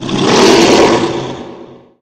sounds / monsters / lurker / ~hit_3.ogg
~hit_3.ogg